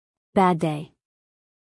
a-bad-day-us-female.mp3